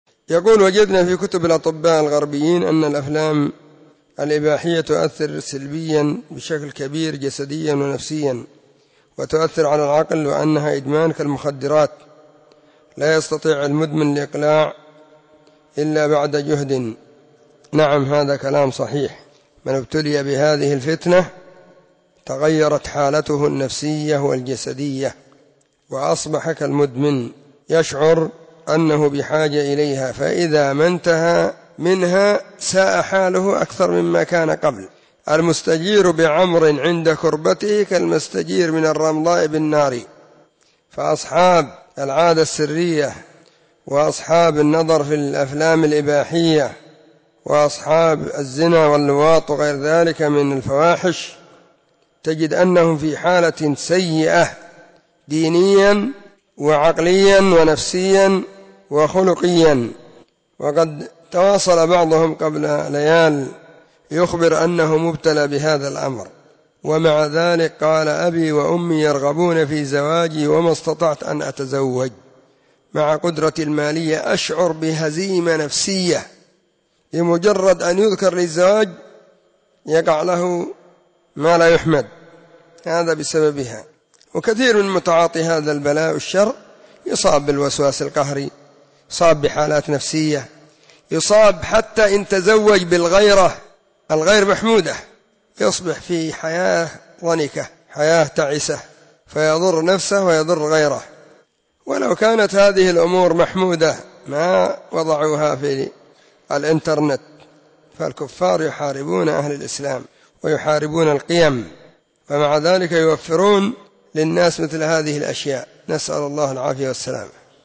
📢 مسجد الصحابة – بالغيضة – المهرة، اليمن حرسها الله.
🔸🔹 سلسلة الفتاوى الصوتية المفردة 🔸🔹